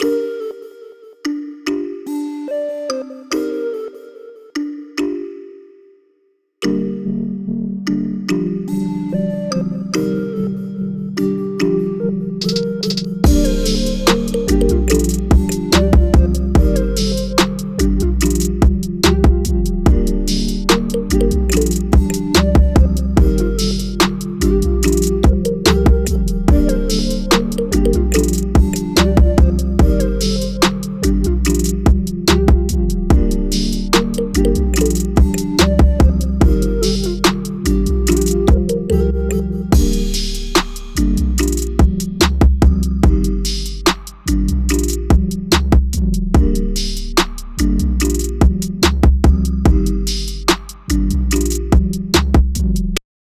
Mixing And Mastering FLP BEAT de TRAP
Mezcla Y Mastering